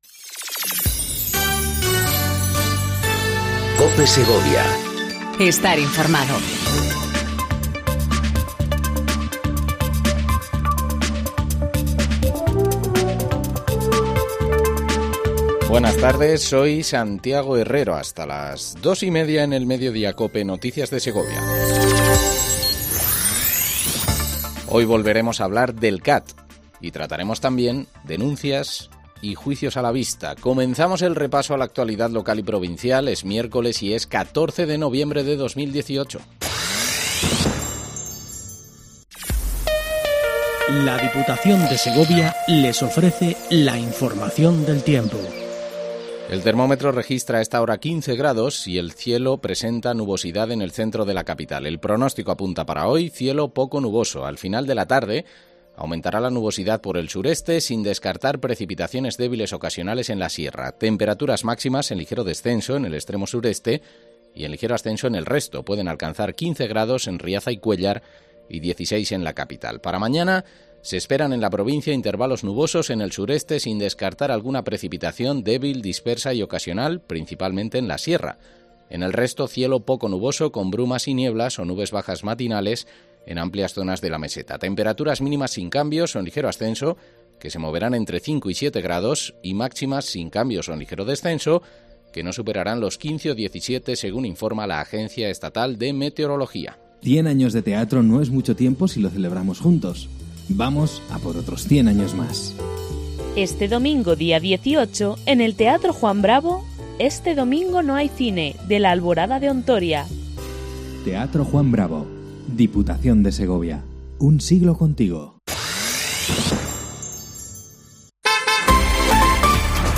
INFORMATIVO MEDIODÍA EN COPE SEGOVIA 14:20 DEL 14/11/18